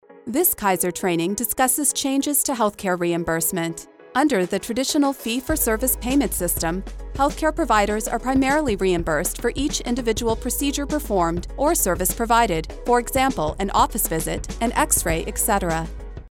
Female voiceover artist, strong, persuasive, high energy, sexy, motivational, modern, millennial, youthful, genuine
E-Learning-Kaiser-Training.mp3